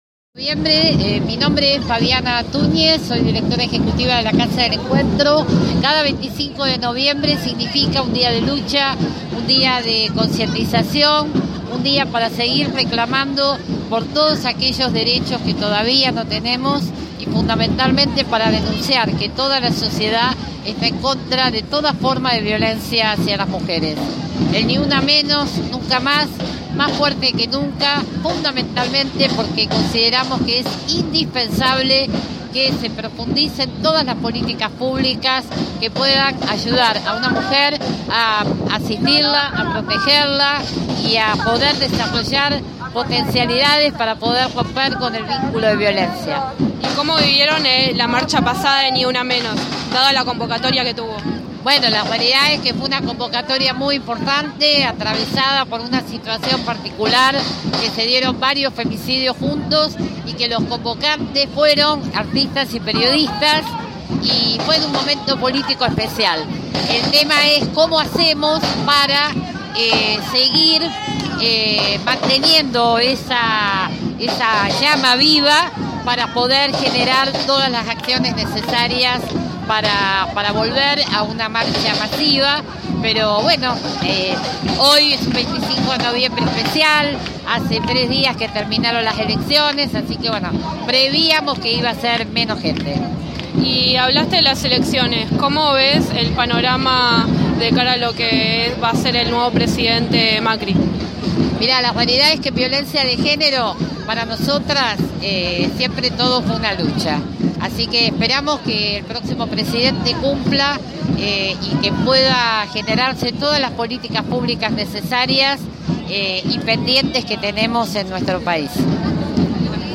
Es por ello que el Día Internacional de la Eliminación de la Violencia contra la Mujer se conmemoró frente al Congreso, en el que se dijo una vez más “Ni una menos”.